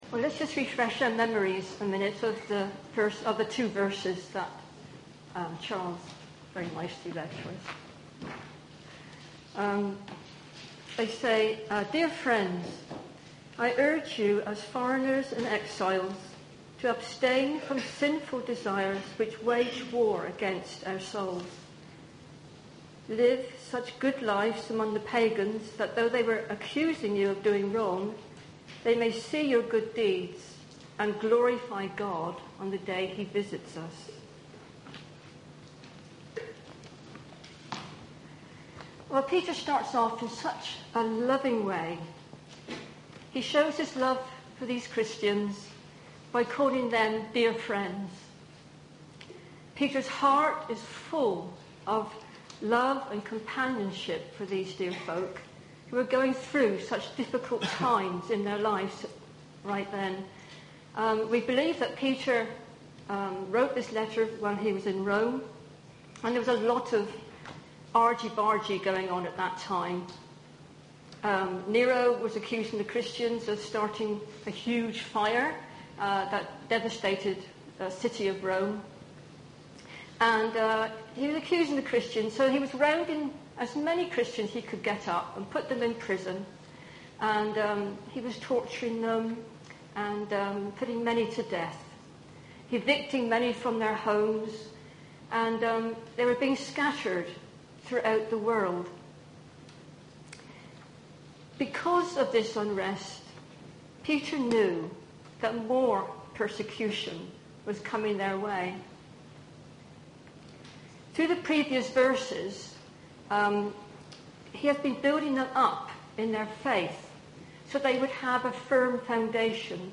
Sermon-8-July-2018.mp3